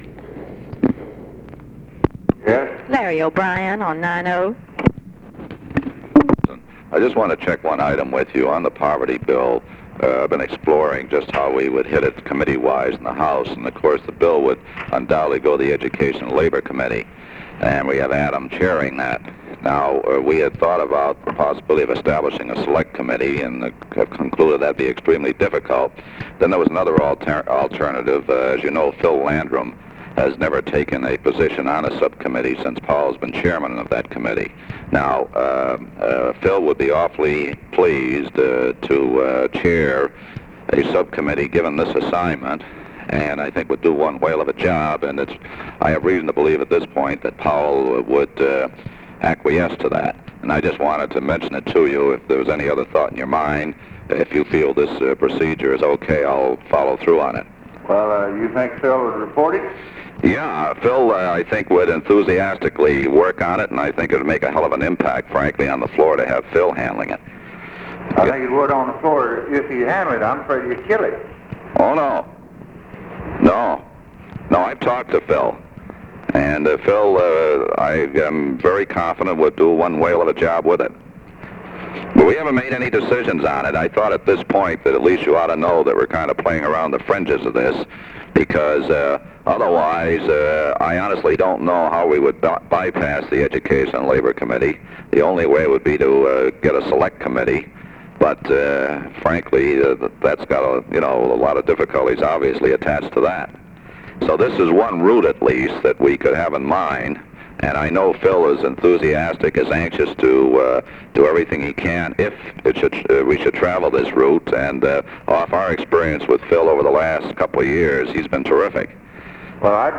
Conversation with LARRY O'BRIEN, February 10, 1964
Secret White House Tapes